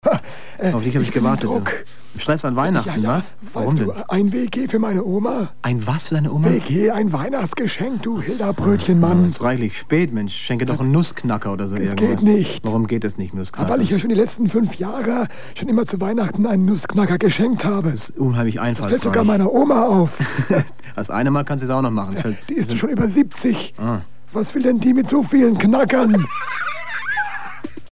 aus den SWF3 Sendungen vor über 40 Jahren aufgenommene Mitschnitte, digitalisiert und hier nun veröffentlicht.
Etwa alle 2 - 3 Wochen nach dem Samstag Mittagessen schaltete man das Radio an und nahm diese Sendungen auf MC auf.